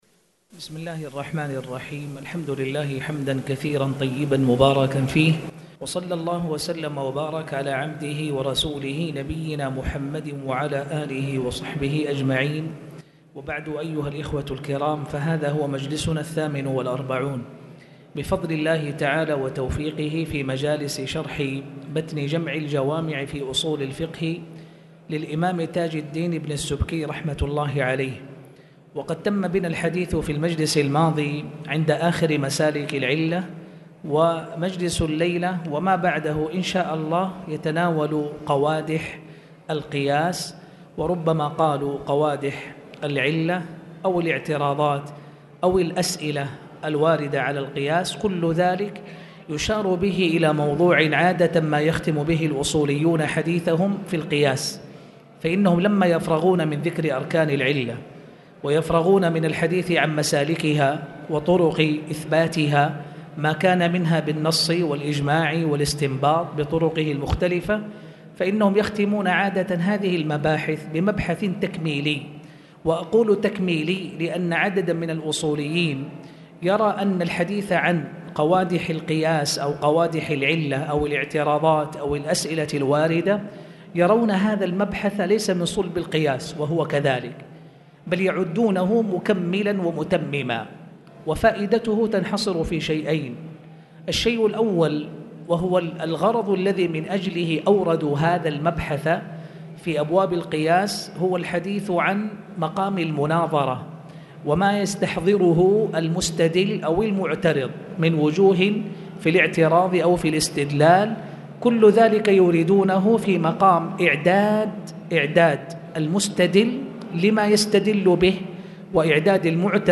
تاريخ النشر ١٣ ربيع الثاني ١٤٣٨ هـ المكان: المسجد الحرام الشيخ